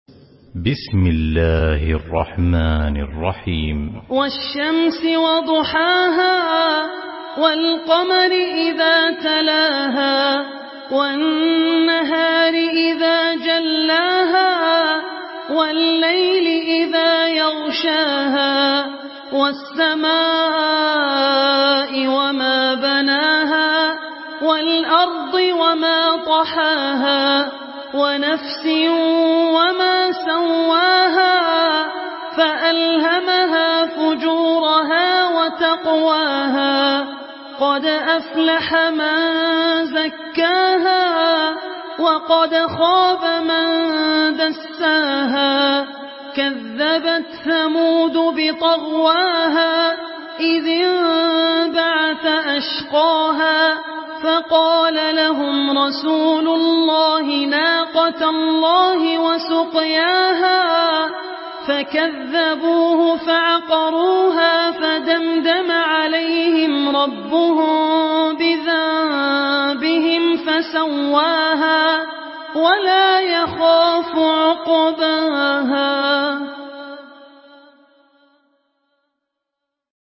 Surah আশ-শামস MP3 by Abdul Rahman Al Ossi in Hafs An Asim narration.
Murattal Hafs An Asim